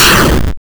boom2.wav